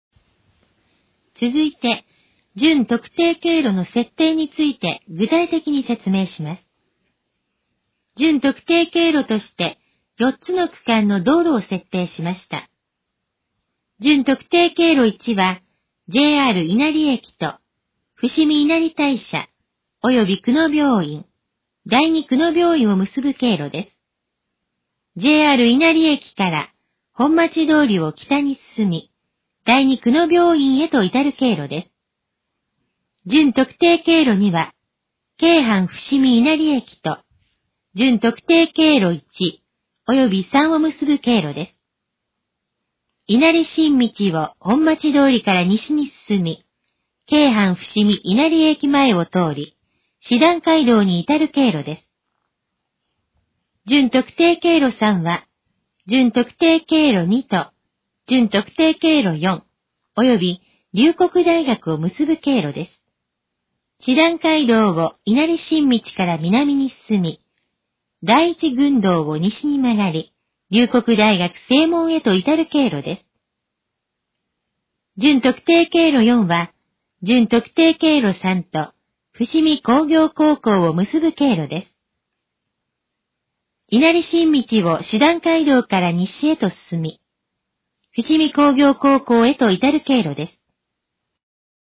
以下の項目の要約を音声で読み上げます。
ナレーション再生 約179KB